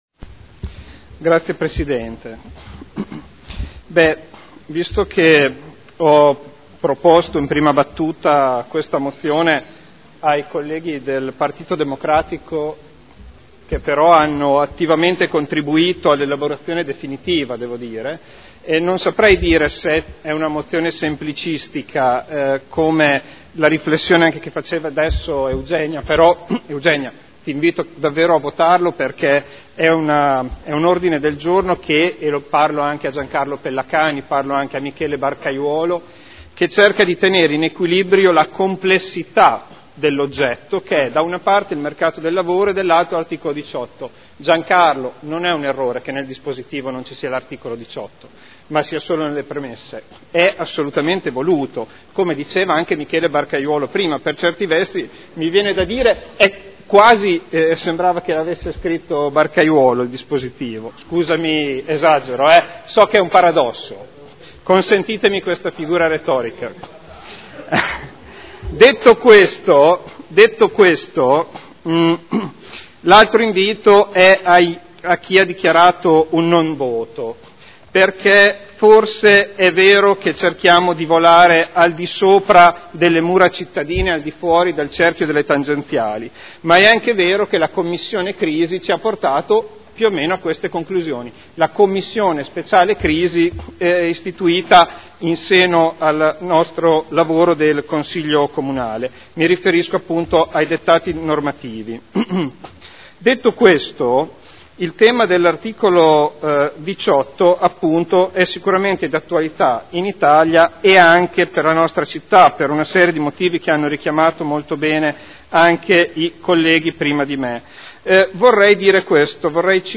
Federico Ricci — Sito Audio Consiglio Comunale
Seduta del 27 febbraio. Mozione presentata dai consiglieri Ricci (Sinistra per Modena) e Trande (P.D.) avente per oggetto: “Riforma del “mercato del lavoro” e Articolo 18 dello Statuto dei Lavoratori: diritti dei lavoratori, delle lavoratrici e relazione con la crescita e occupazione” Dibattito